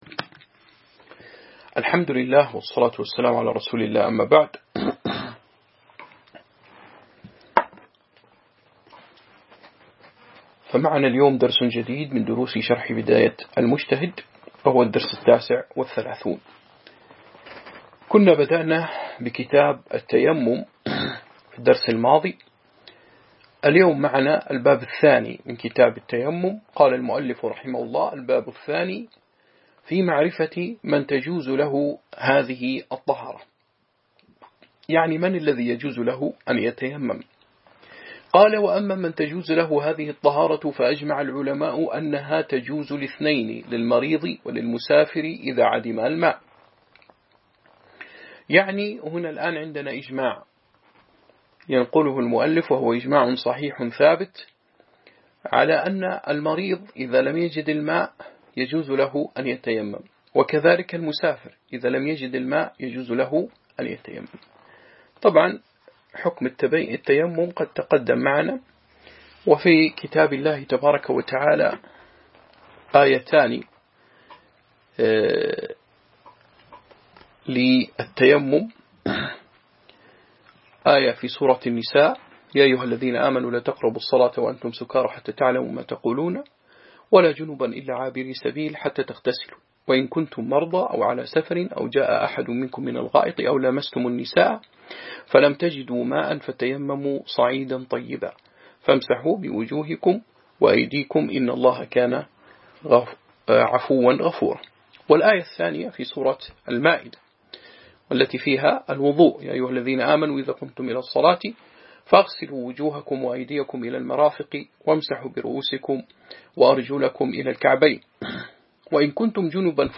شرح بداية المجتهد و نهاية المقتصد الدرس 39 كتاب التيمم، من يجوز له أن يتيمم